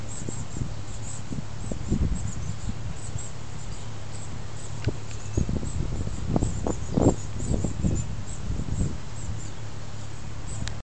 Birdsong
Scientists have determined that in order to make this noise, the animal must have been smaller than a Paczki.